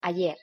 Pronunciation Es Ayer (audio/mpeg)